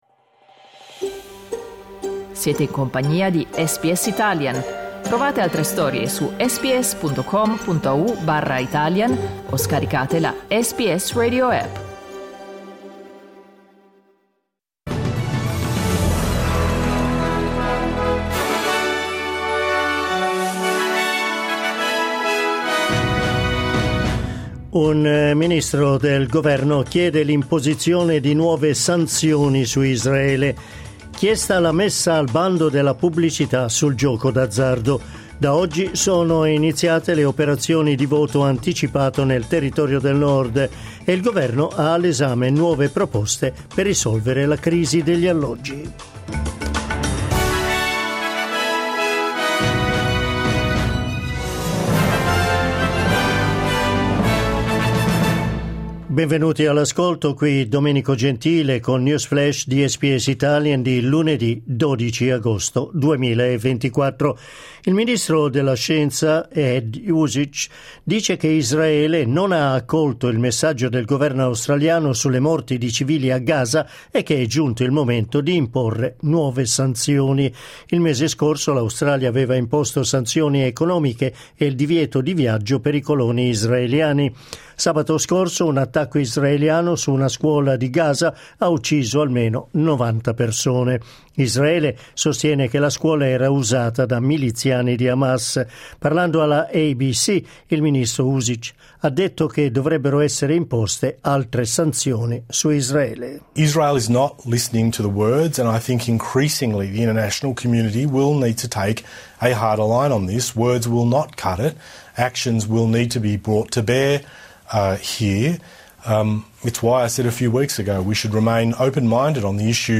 News flash lunedì 12 agosto 2024